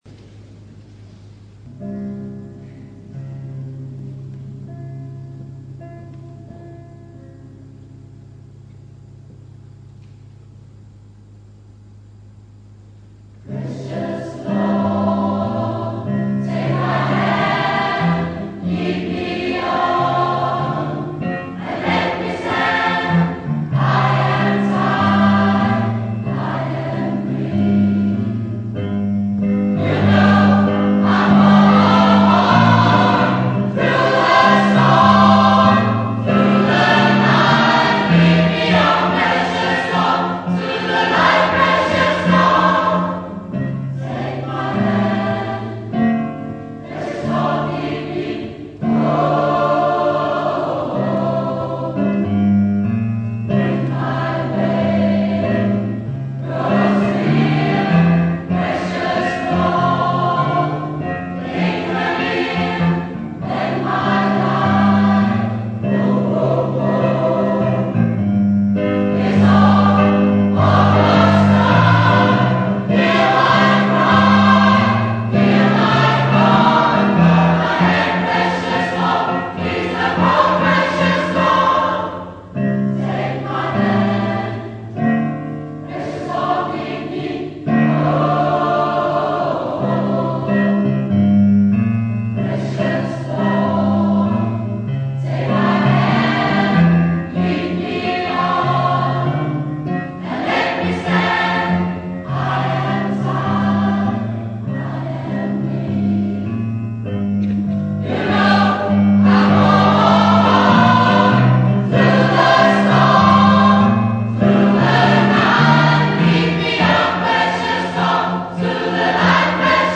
15.12.2005 Julekoncert Sct Laurentii